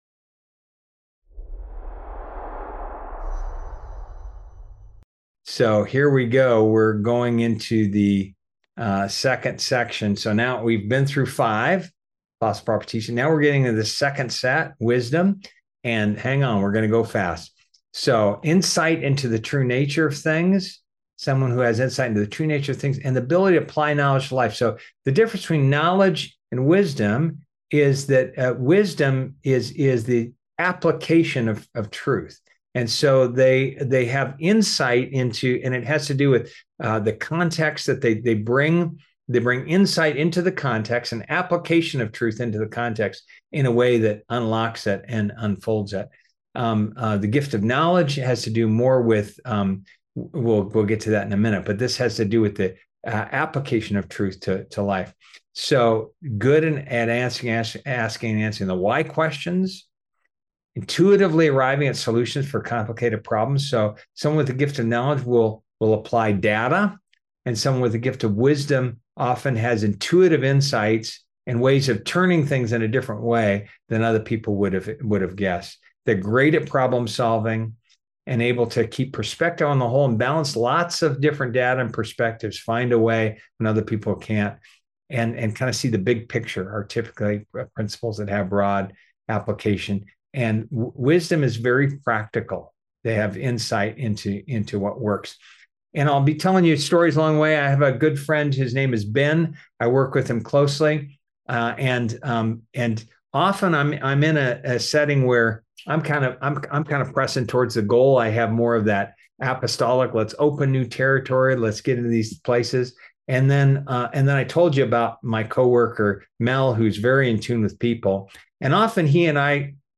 Event: Master Class